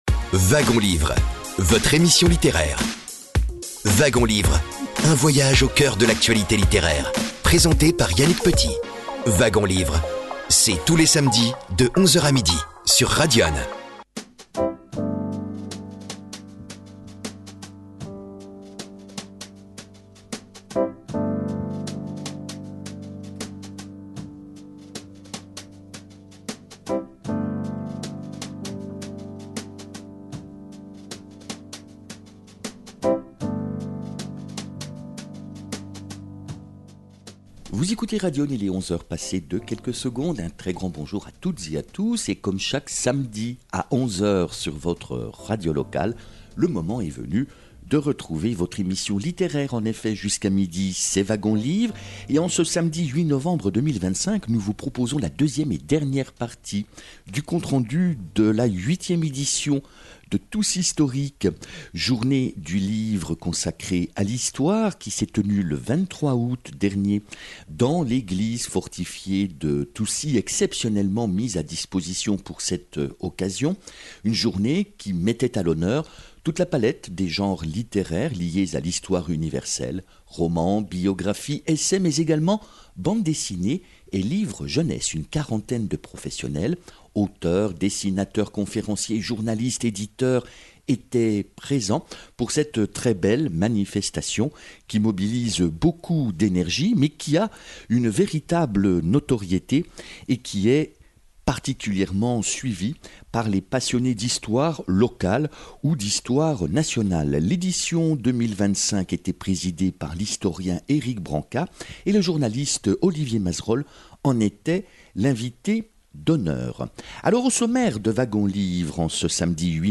À cette occasion, nous avons enregistré des reportages avec quelques uns des auteurs invités :